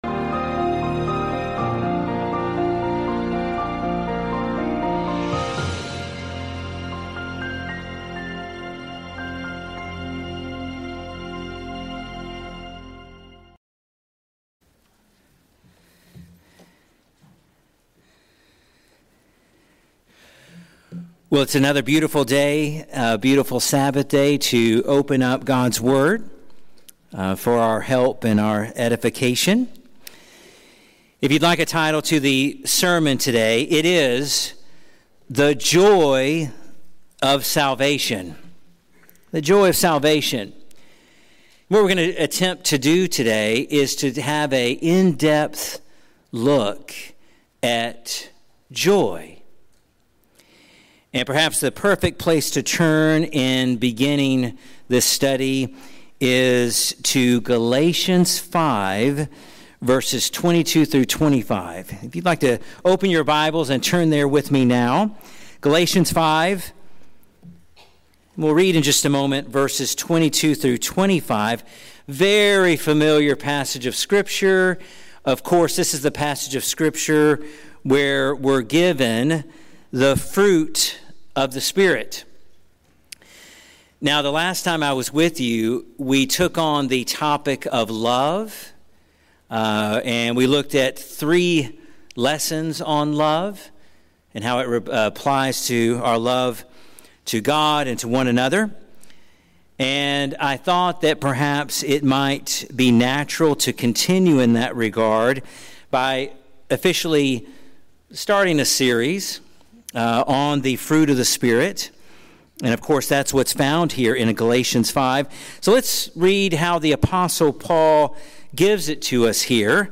This sermon teaches that true joy is not mere happiness tied to circumstances, but the supernatural Joy of Salvation produced by the Holy Spirit.